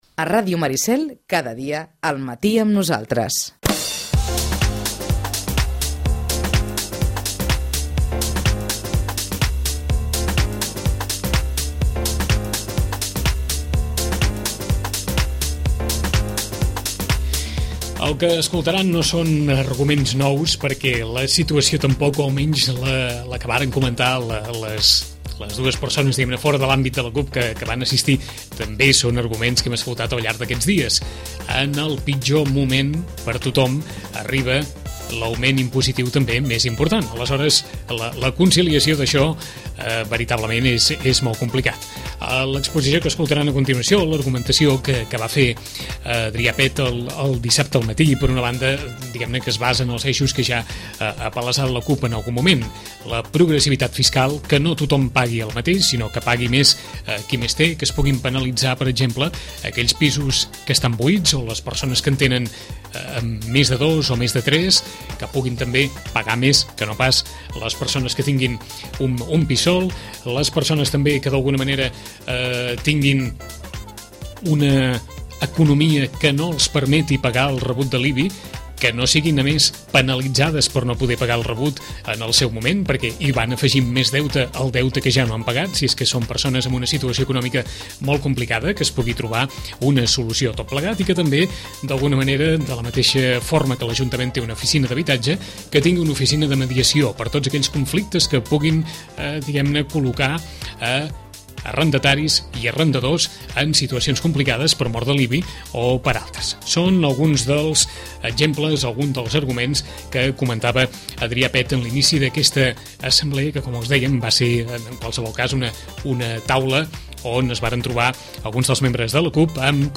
En l’assemblea oberta sobre l’IBI que se celebrà a l’ateneu de Sitges el passat dissabte al matí, la CUP exposà la necessitat que l’Ajuntament contempli una fiscalitat progressiva i analitzi amb més detall els casos de titulars de rebuts amb situació econòmica precària. La formació creu que el municipi també hauria de comptar amb un servei de mediació per a resoldre conflictes entre parts.